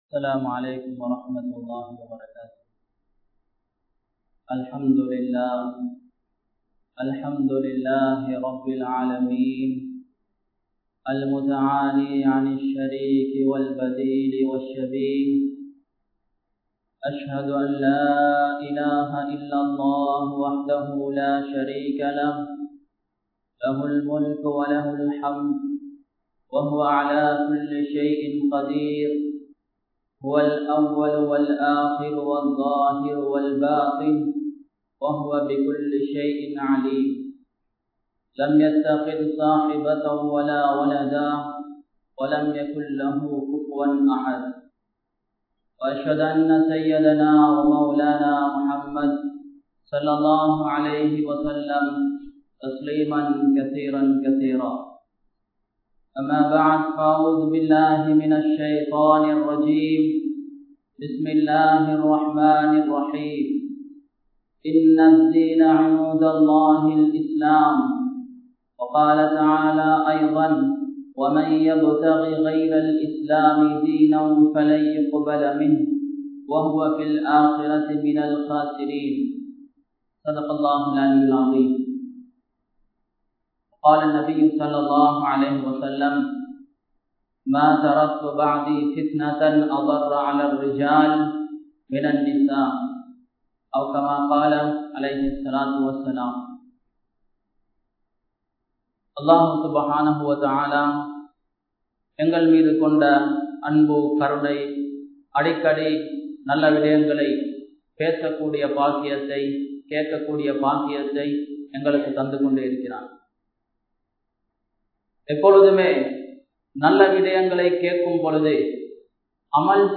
Thappikka Mudiyatha Maranam (தப்பிக்க முடியாத மரணம்) | Audio Bayans | All Ceylon Muslim Youth Community | Addalaichenai
Masjithur Ravaha